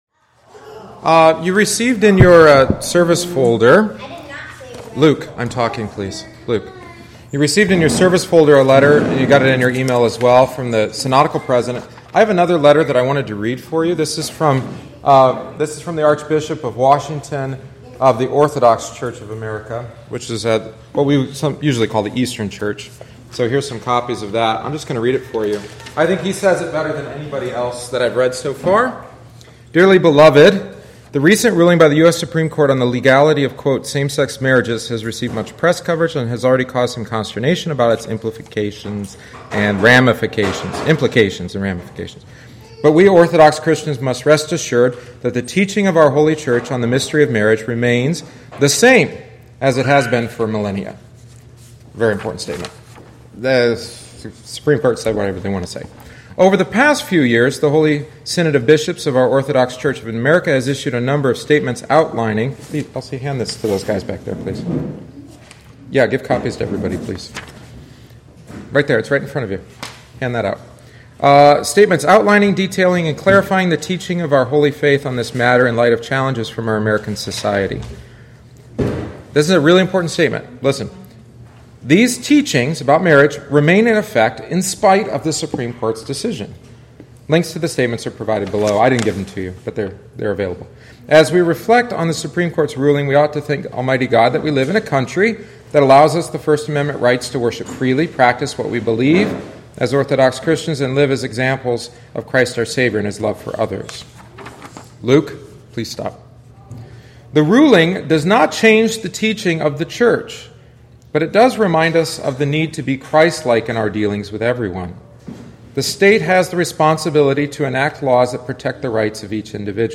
Bible Study on Judging and Condemning